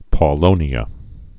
(pô-lōnē-ə)